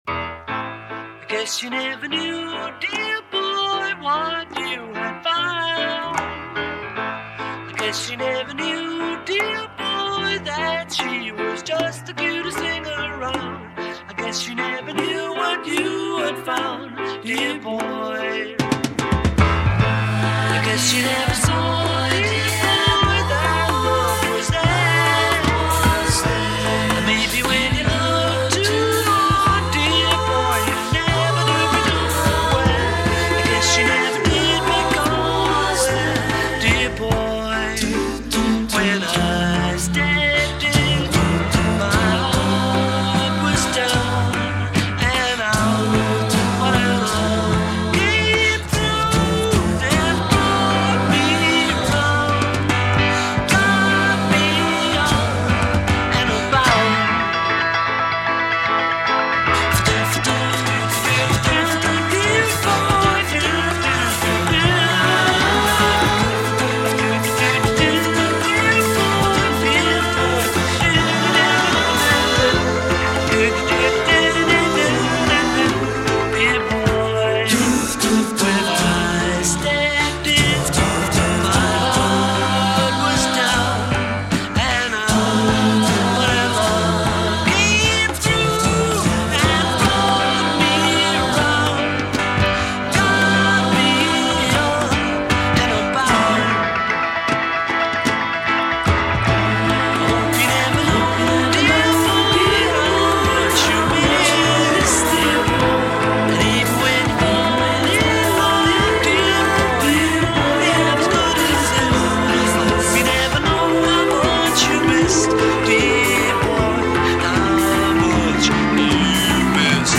записанный в январе-марте 1971 года в RCA Studios, Нью-Йорк